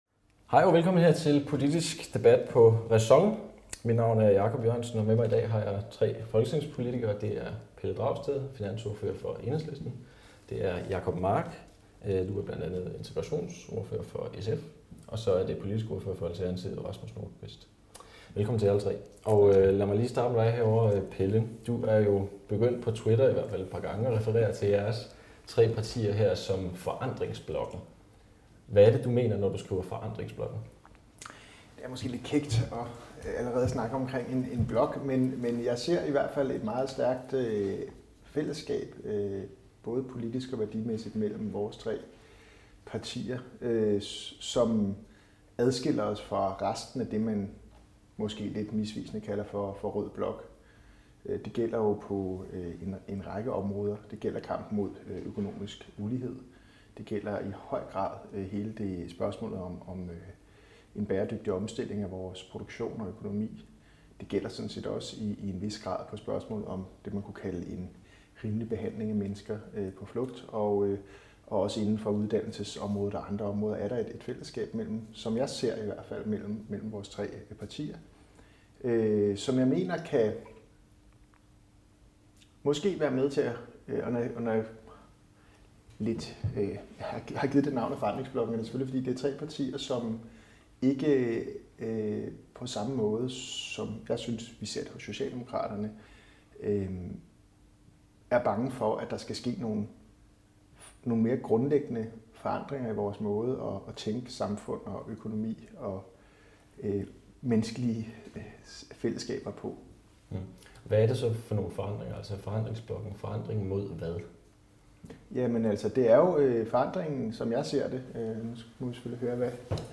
Rundbordsdebat-Forandringsblokken2.mp3